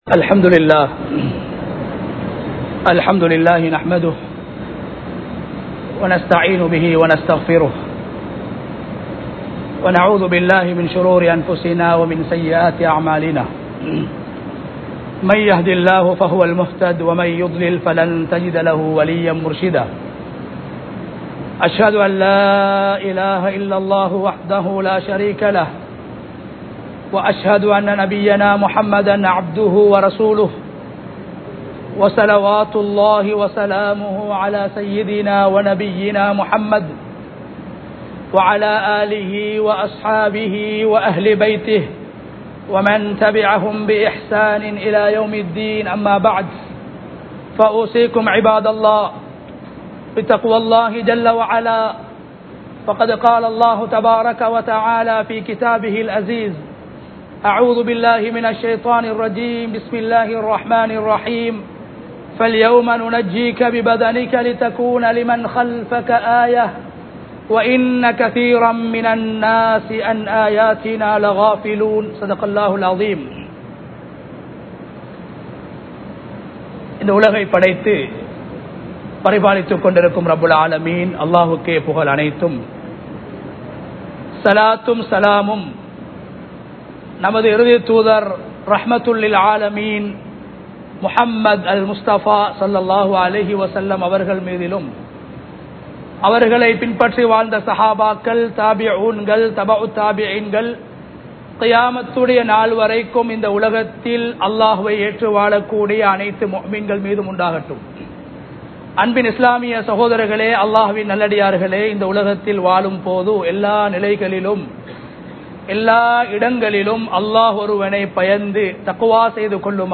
அல்குர்ஆன் கூறும் முஹர்ரம் | Audio Bayans | All Ceylon Muslim Youth Community | Addalaichenai